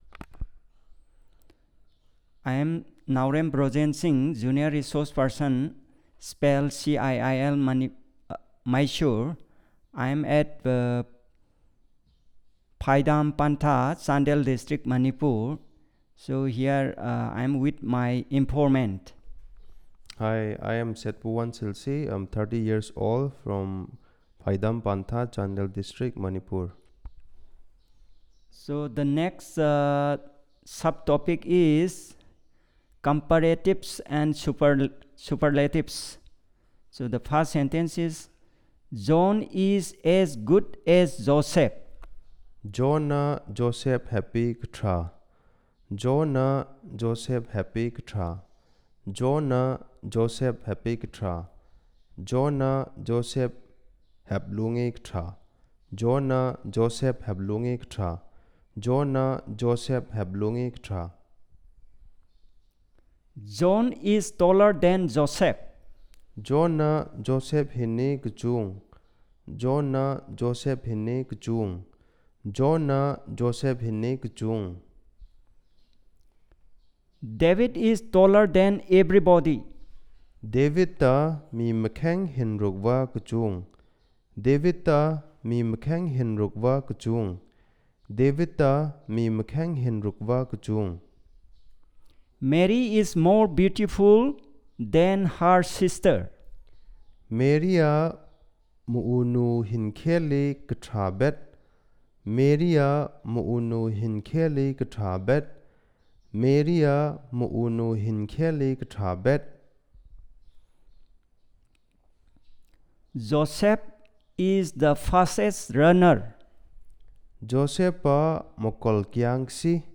Elicitation of sentences about comparatives and superlatives